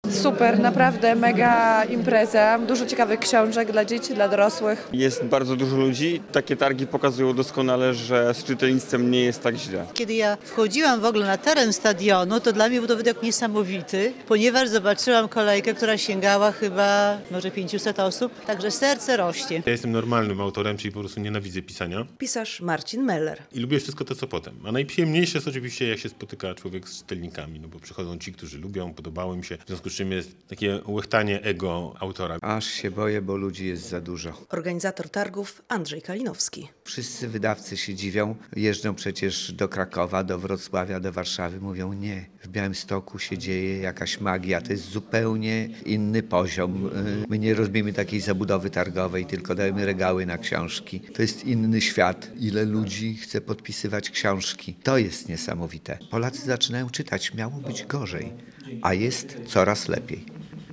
Na Stadionie Miejskim w Białymstoku trwa drugi dzień jubileuszowych, dziesiątych Targów Książki.